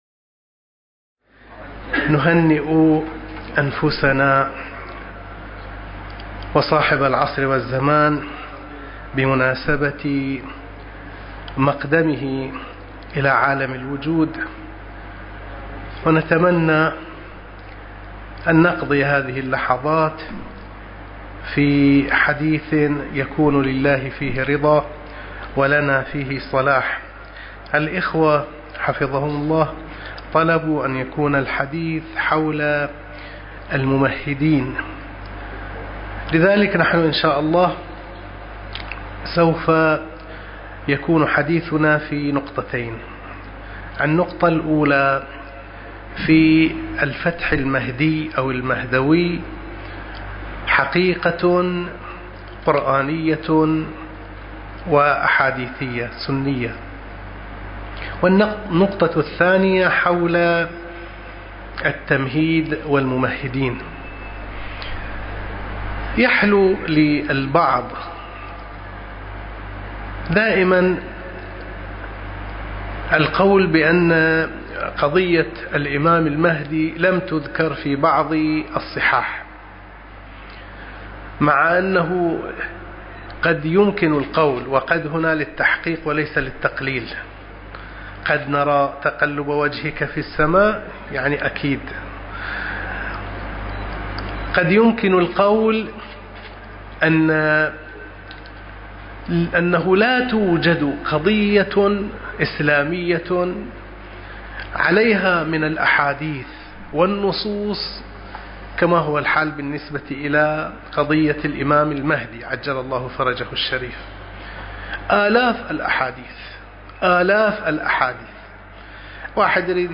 المكان: مأتم الغرب - البحرين